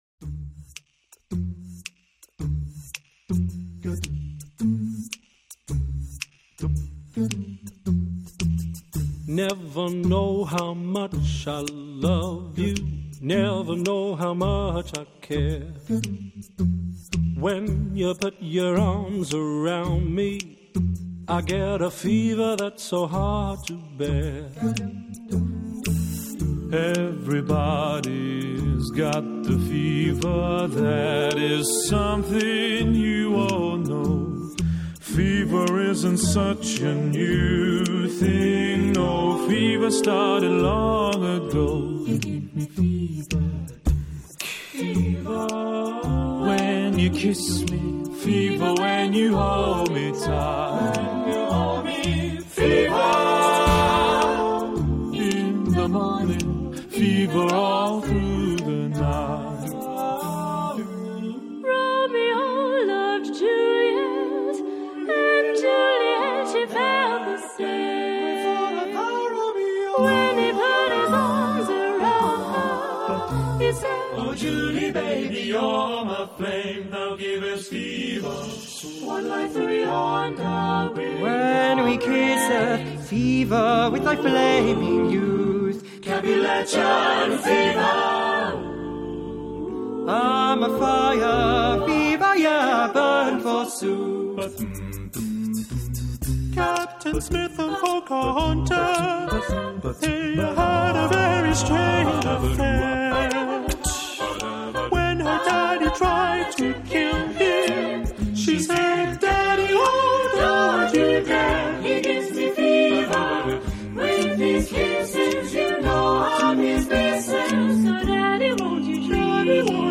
Genre-Style-Form: Secular ; Vocal jazz ; Jazz standards
Mood of the piece: nonchalant ; swing
Type of Choir: SSAATTBB  (8 mixed voices )
Tonality: D minor